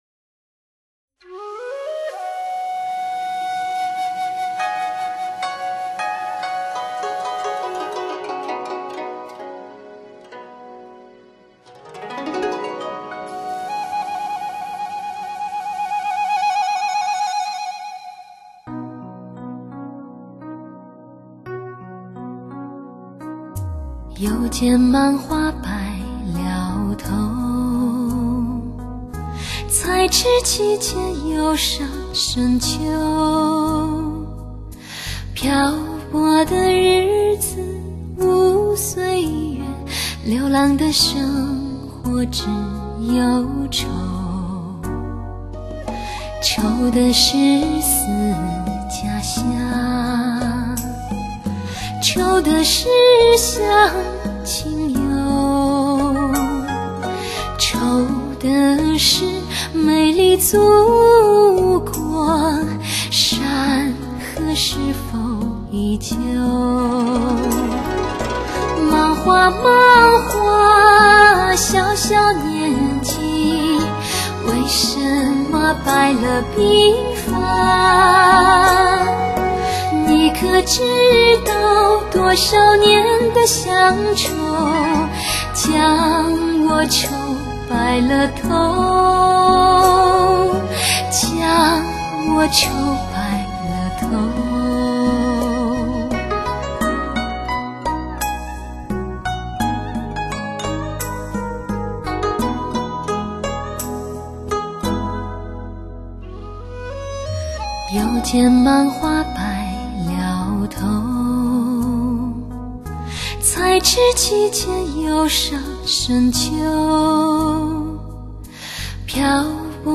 （试听为低品质wma，下载为320k/mp3）